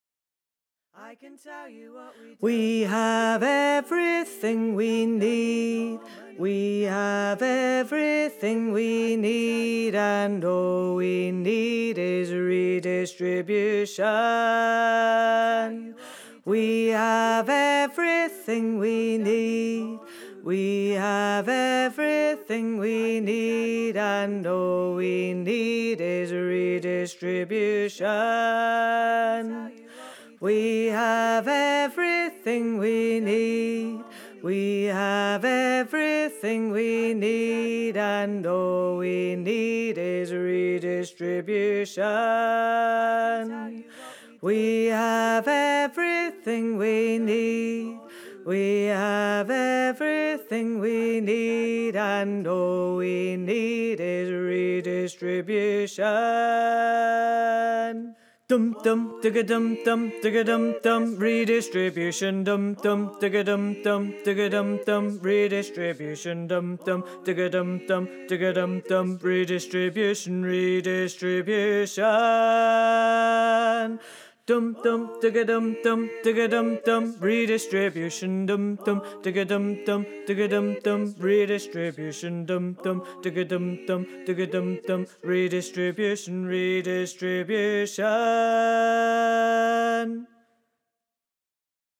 Bass:
redistribution_learning-tracks_bass.wav